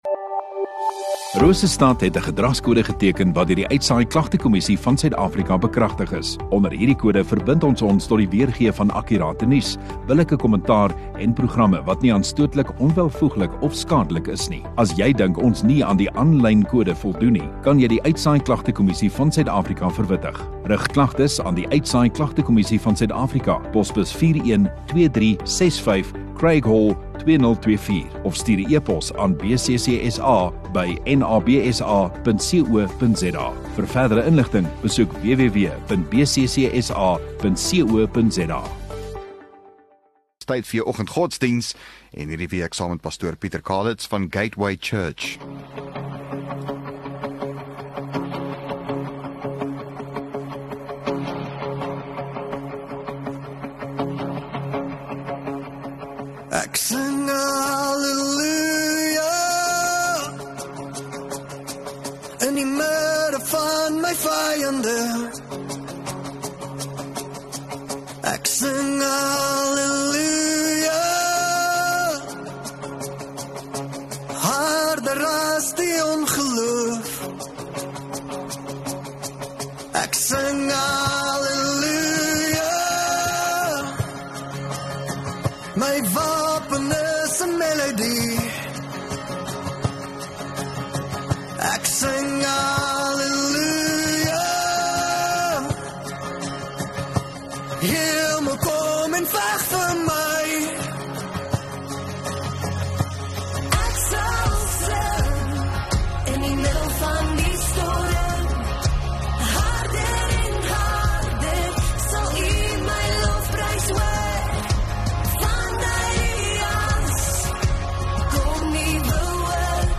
6 Jun Vrydag Oggenddiens